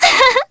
Yanne_L-Vox_Happy4_jp.wav